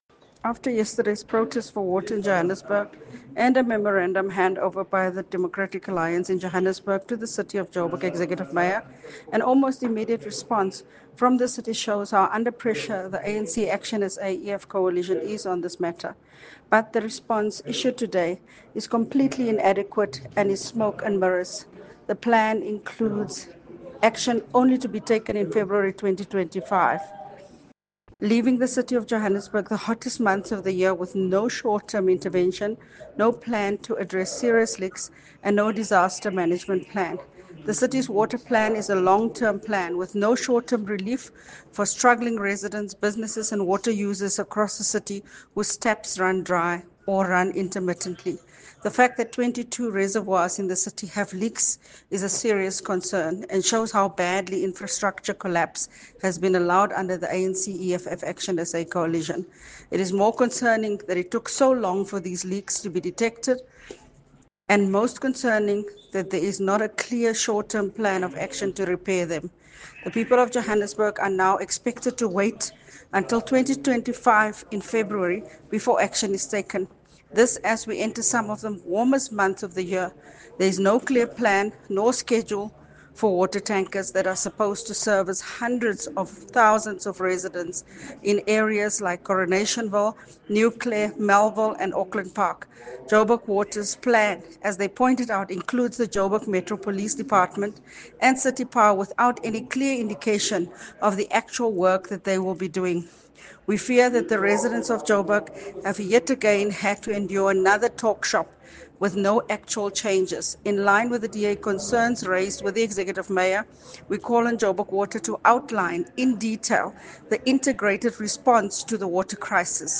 Note to Editors: Please find a soundbite by Cllr Belinda Kayser-Echeozonjoku